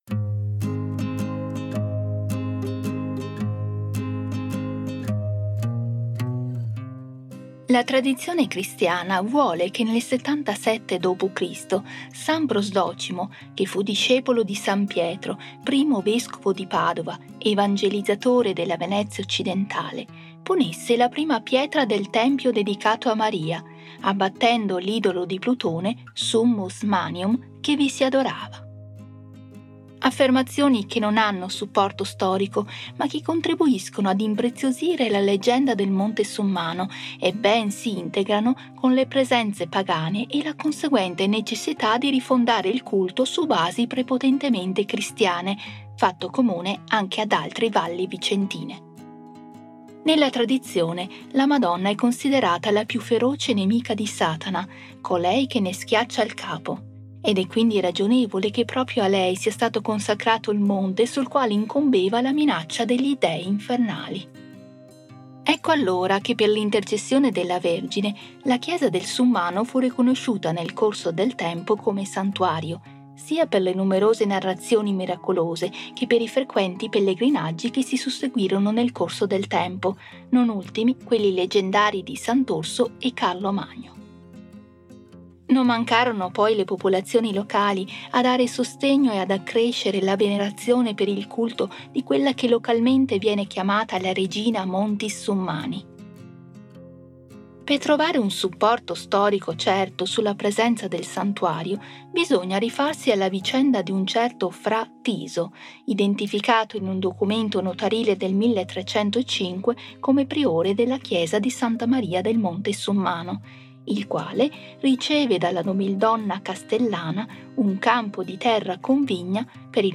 AUDIOGUIDA_Girolimini._07._Da_San_Prosdocimo_ai_padri_Girolimini.mp3